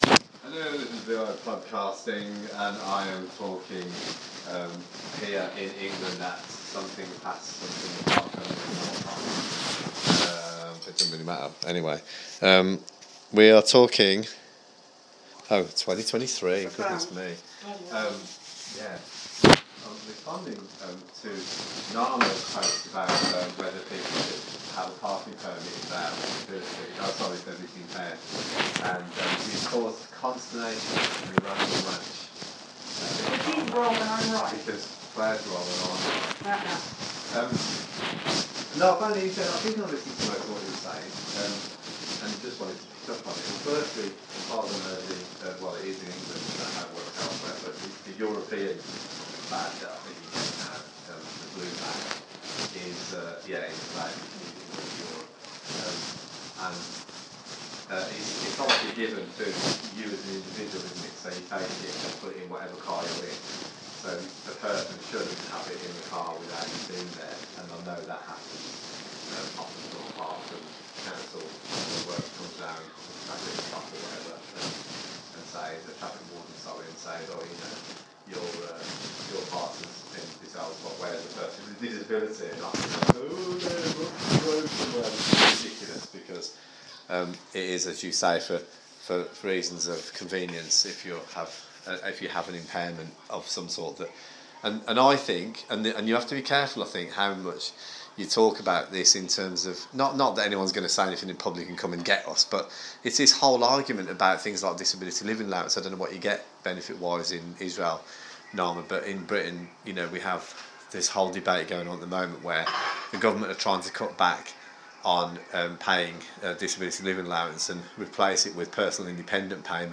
A discussion ensues around the parking permits for disabled people